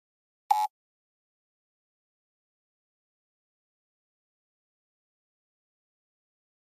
Flash Alarm Low Frequency Electronic Buzz